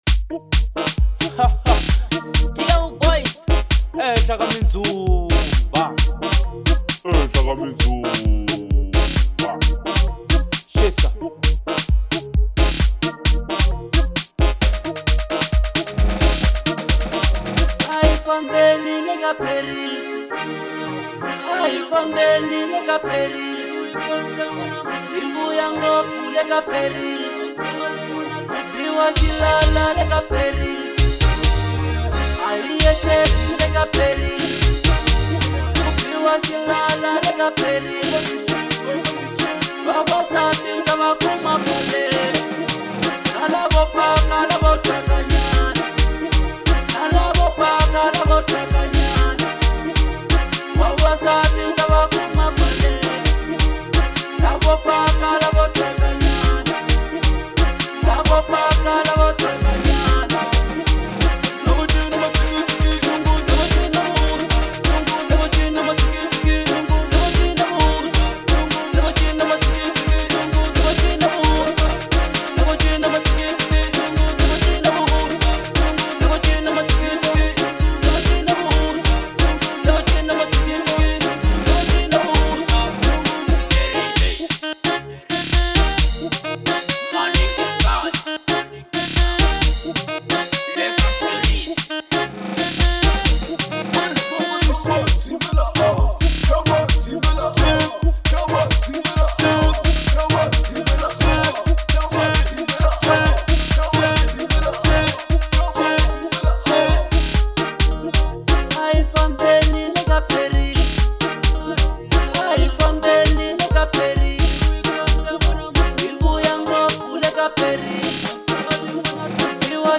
04:12 Genre : Local House Size